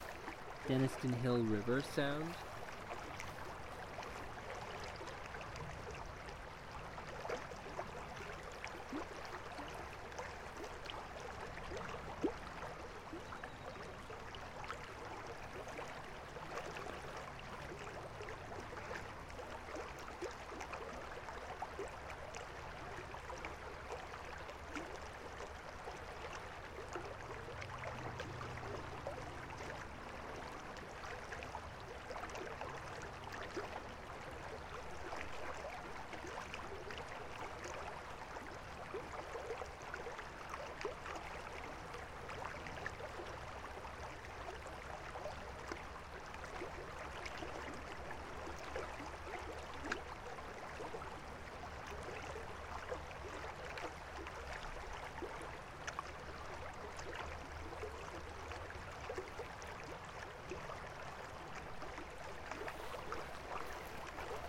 Water » Water Flowing Through Distant Rapids 3
描述：Distant field recording of water flowing through some rapids in a creek. Recorded at Springbrook National Park, Queensland using the Zoom H6 Midside module.
标签： creek gurgle trickle flowing distant water brook flow liquid stream fieldrecording river
声道立体声